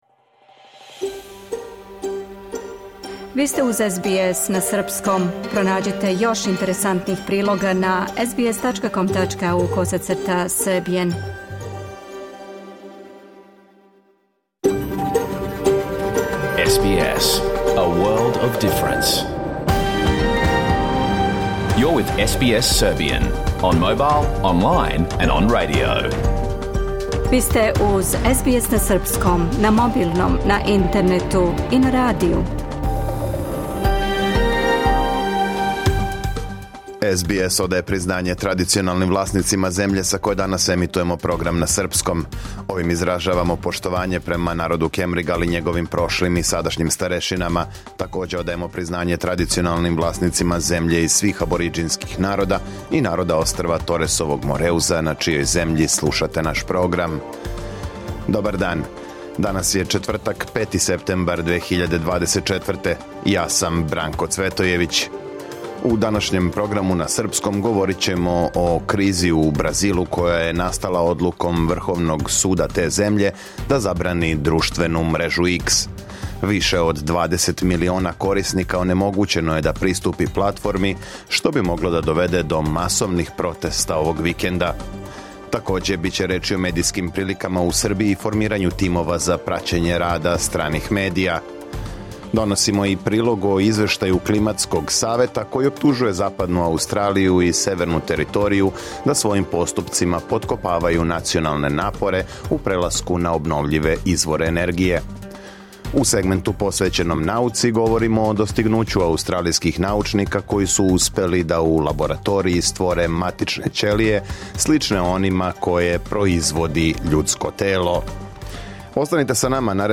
Програм емитован уживо 5. септембра 2024. године
Уколико сте пропустили данашњу емисију, можете је послушати у целини као подкаст, без реклама.